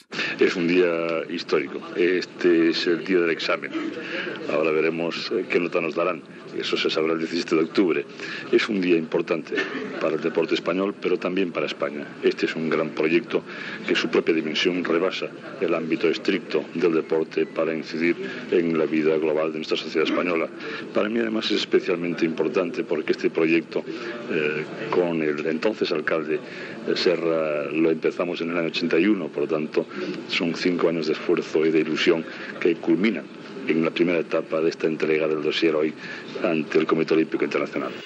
Presentació del dossier de la candidatura de Barcelona per organitzar els Jocs Olímpics de Barcelona l'any 1992 al Comitè Olímpic Internacional (declaració del Secretari d'Estat per a l'Esport Romà Cuyàs)
Informatiu